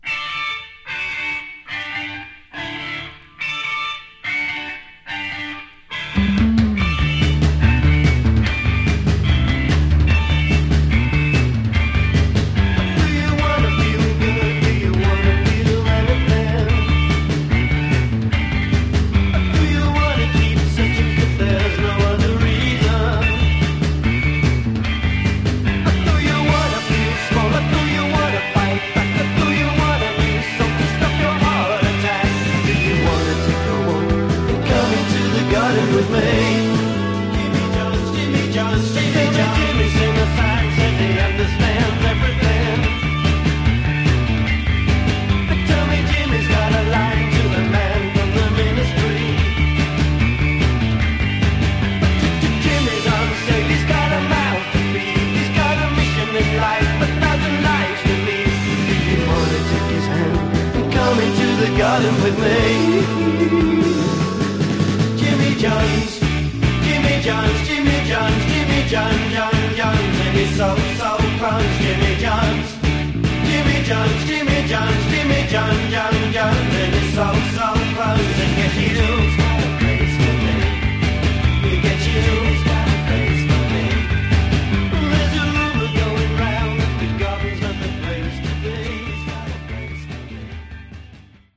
Genre: Modern Rock
almost-punk anthem